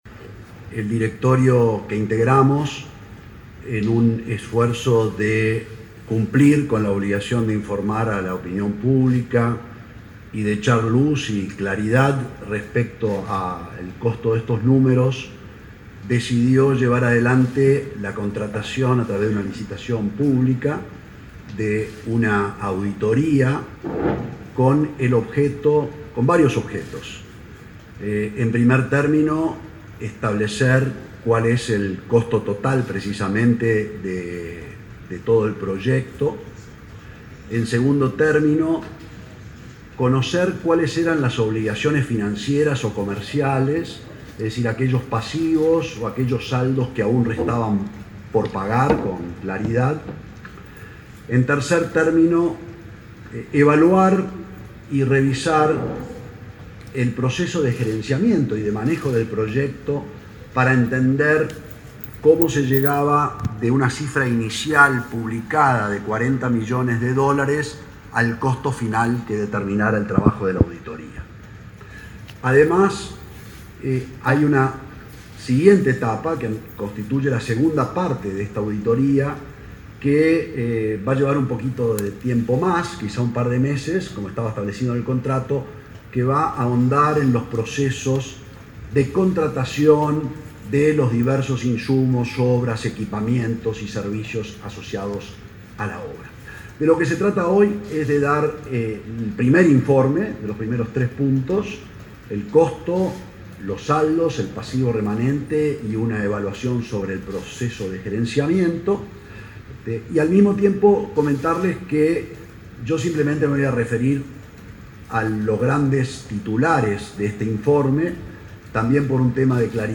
Conferencia de prensa del presidente de Antel, Gabriel Gurméndez.
El presidente de Antel, Gabriel Gurméndez, brindó este martes 22 una conferencia de prensa para informar detalles de la auditoría de gestión realizada